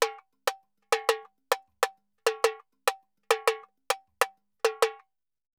Tamborin Salsa 100_2.wav